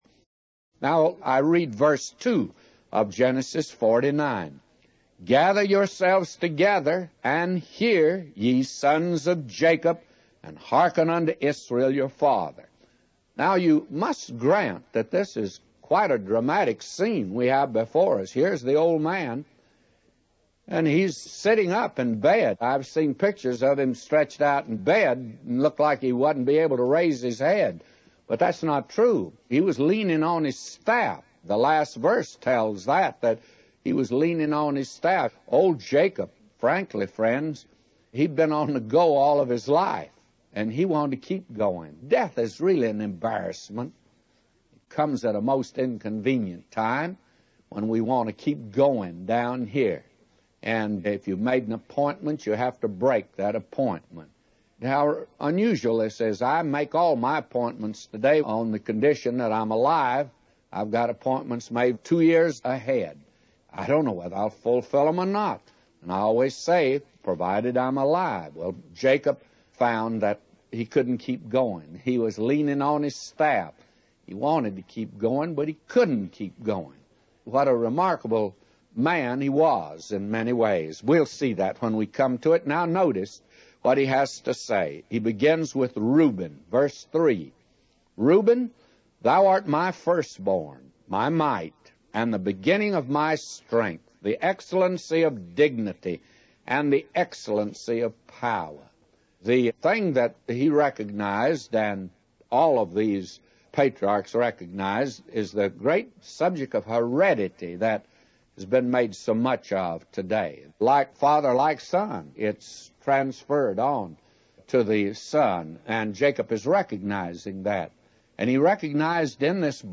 A Commentary By J Vernon MCgee For Genesis 49:2-999